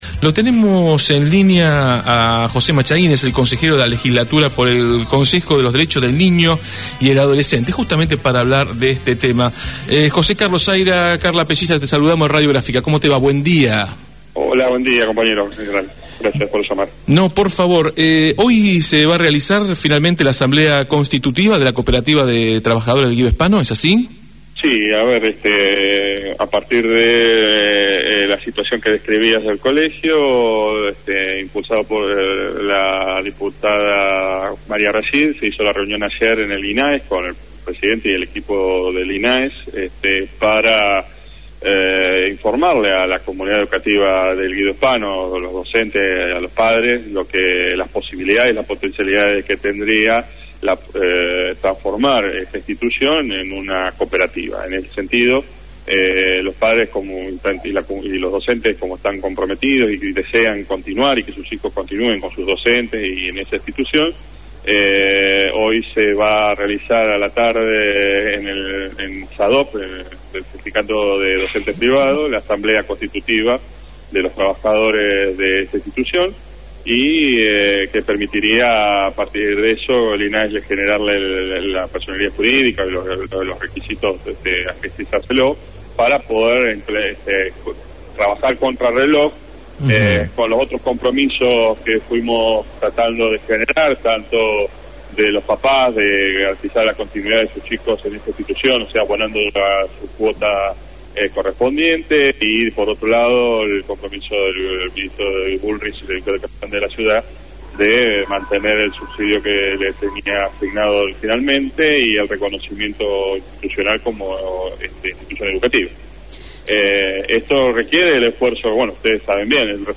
José Machain, Consejero de la Legislatura porteña por el Consejo de los Derechos del Niño y el Adolescente, fue entrevistado en Desde el Barrio sobre la conformación de la cooperativa de trabajo del colegio Guido Spano, cuya asamkblea constitutiva tendrá lugar a las 16 en Medrano 1685.